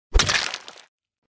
Among Us Stationary Kill Sound Effect Free Download